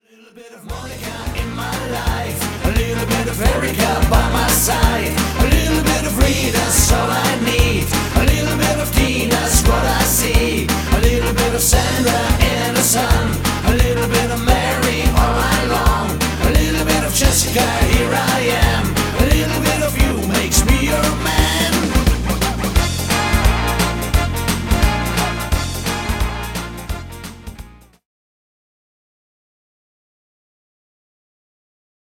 Pop / Rock